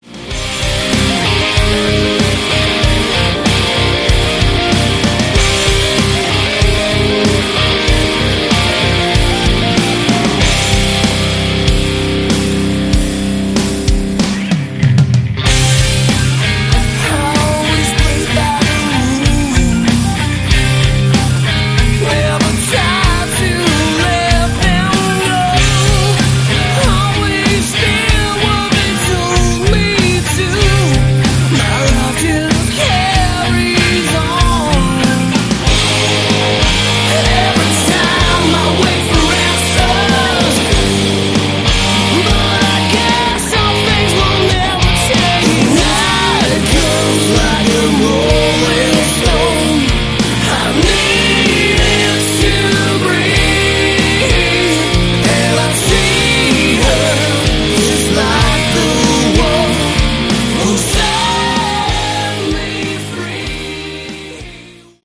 Category: Melodic Hard Rock
lead and backing vocals
guitars
bass
drums
keyboards